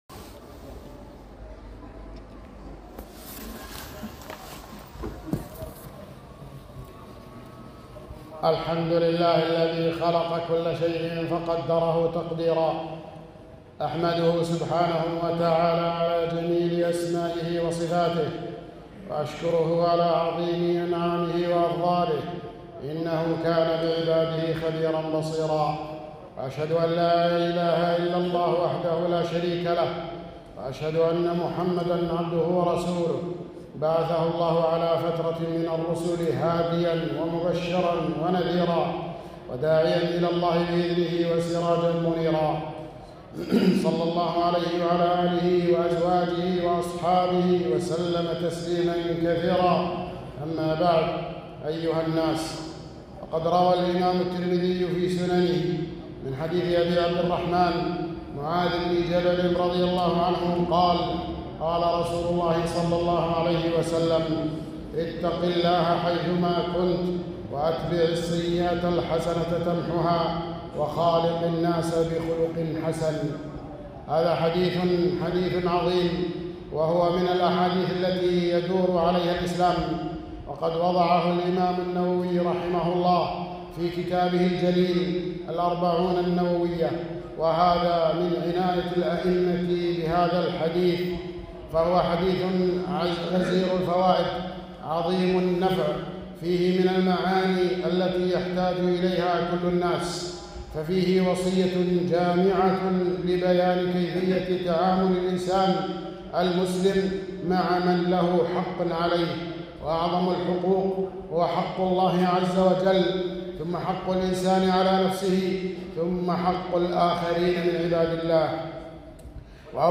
خطبة - من الوصايا النبوية